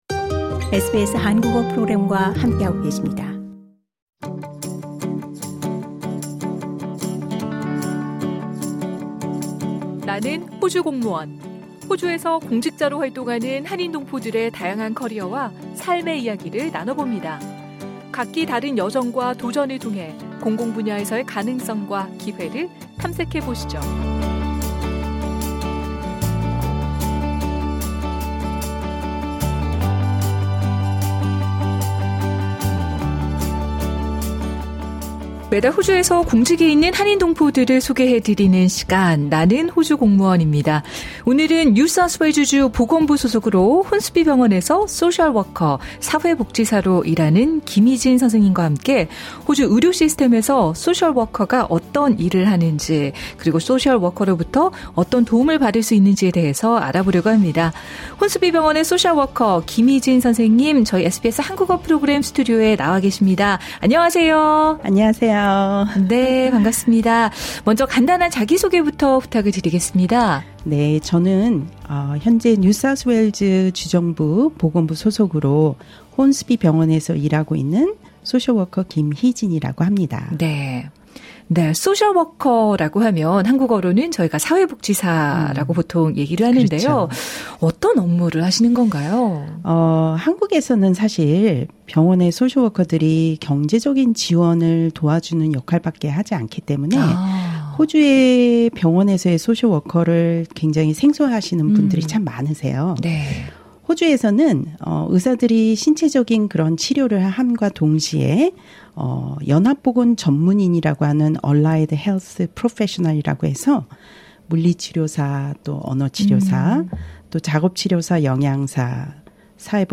저희 SBS 한국어 프로그램 스튜디오에 나와계십니다.